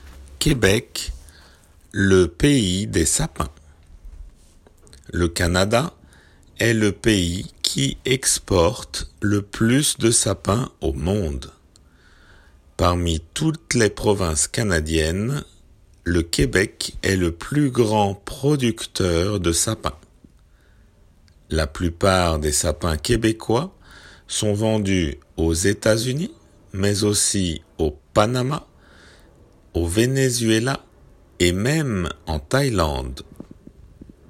普通の速さで